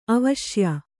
♪ avaśya